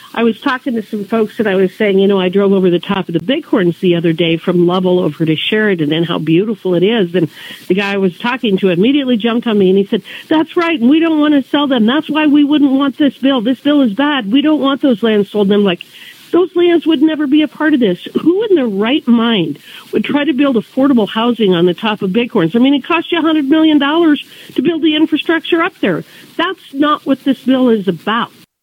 During her June 19th “Weekday Wake Up” radio program, Congresswoman Hageman said that any federal land considered for sale must be located within one to five miles of a community identified as lacking affordable housing.